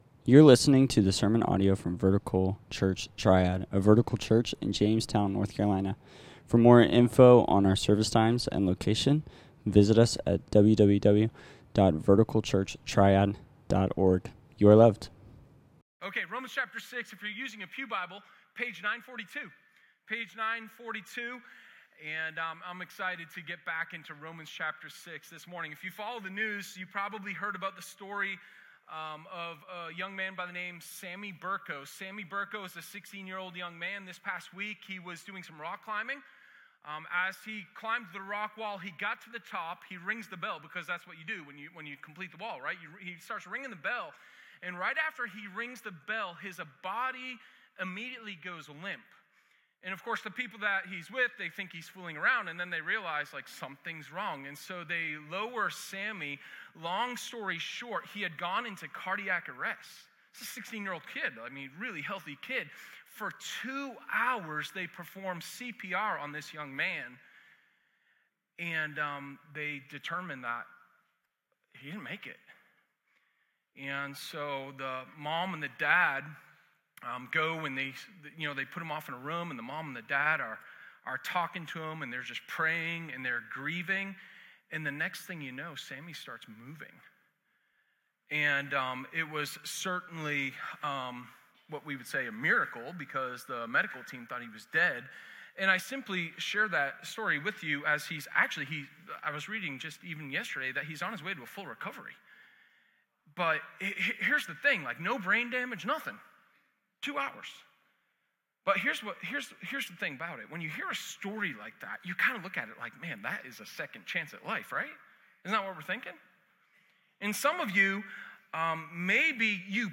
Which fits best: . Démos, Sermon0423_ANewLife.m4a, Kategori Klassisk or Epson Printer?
Sermon0423_ANewLife.m4a